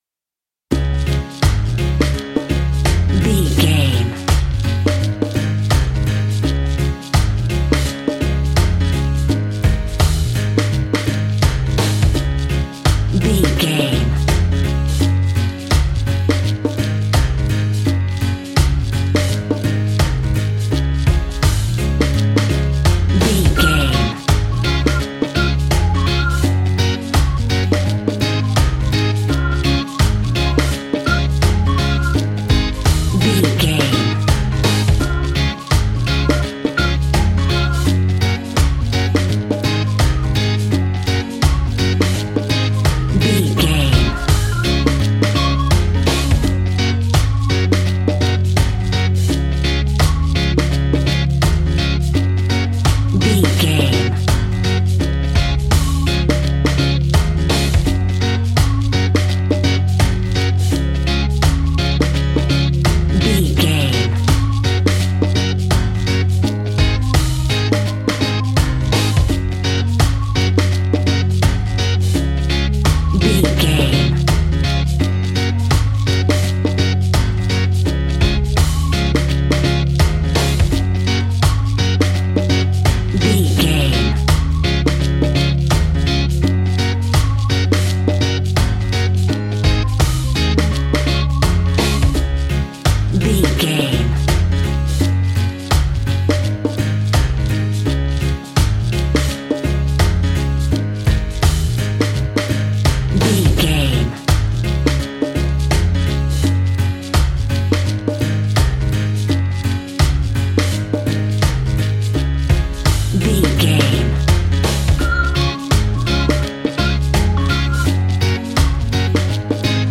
Uplifting
Ionian/Major
D♭
steelpan
calypso music
drums
percussion
bass
brass
guitar